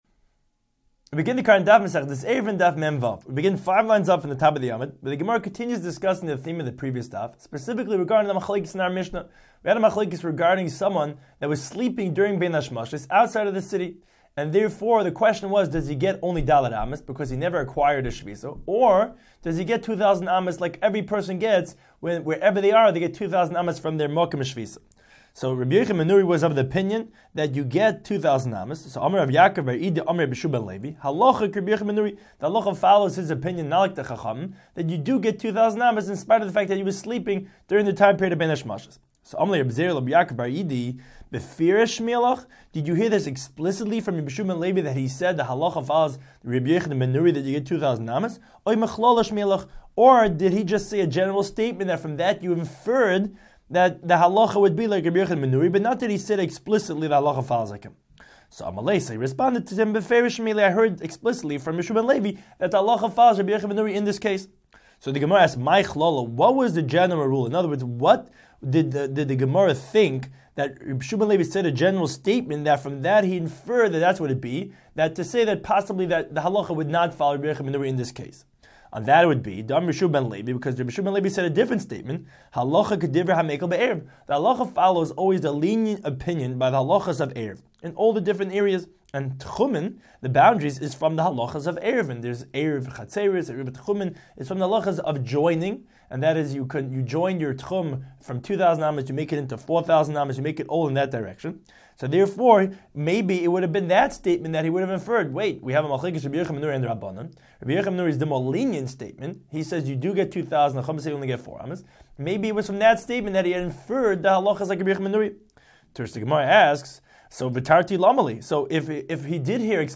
Daf Hachaim Shiur for Eruvin 46